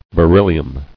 [be·ryl·li·um]